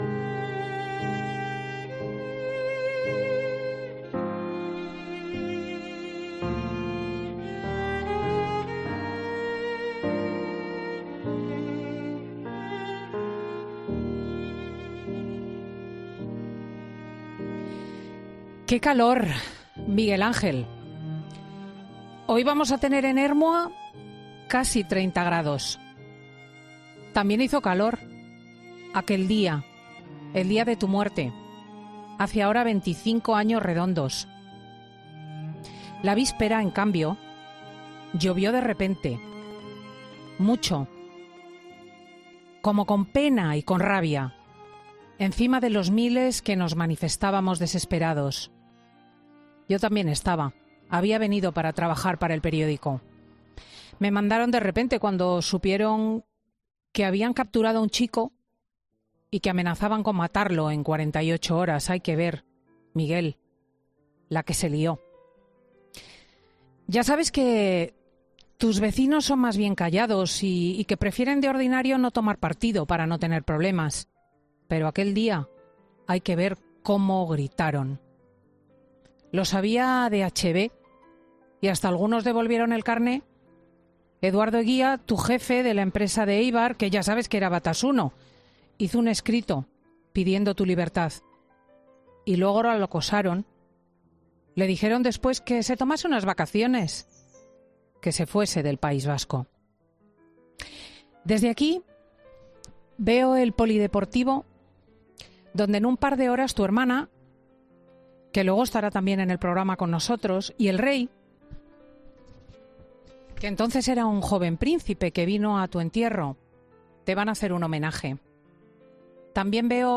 Monólogo de Cristina López Schlichting